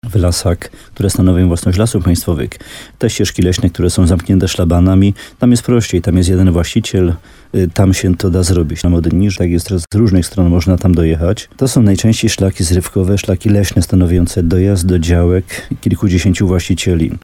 Turyści narzekają na hałas, a także rozjeżdżane i niszczone przez te pojazdy szlaki. Jak mówił poranny gość RDN Nowy Sącz, wójt Jan Dziedzina, ograniczenie ruchu szlabanem w tym miejscu jest problematyczne.